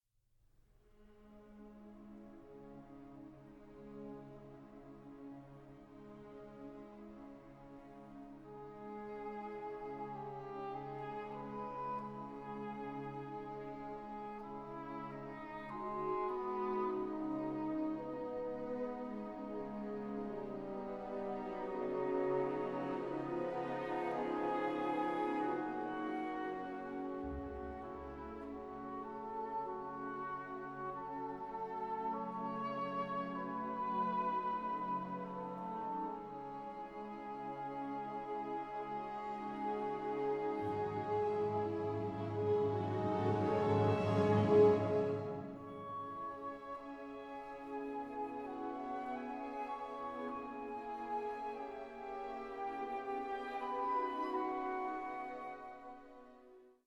Multi-ch / Stereo
cor anglais